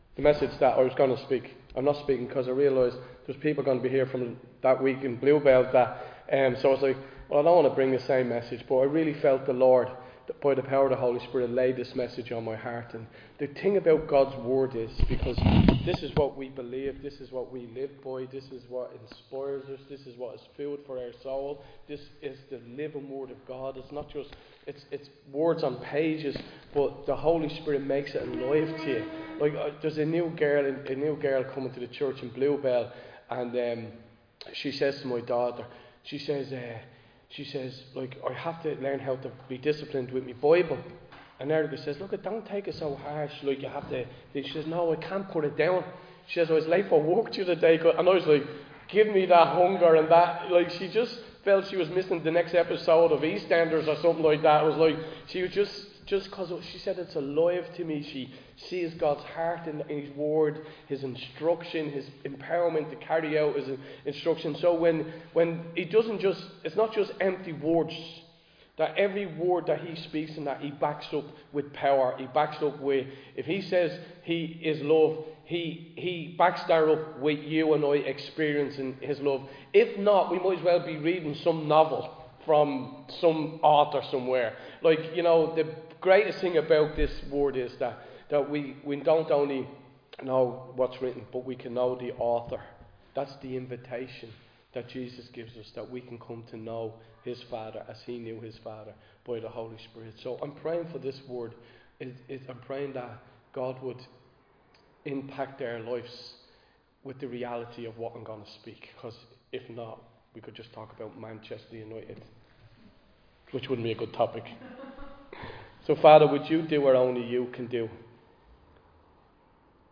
delivers a message on the perfect peace given by God Recorded live in Liberty Church on 12 January 2025